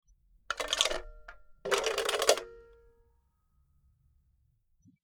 Rotary Phone
Rotary_phone.mp3